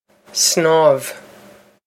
Pronunciation for how to say
snawv
This is an approximate phonetic pronunciation of the phrase.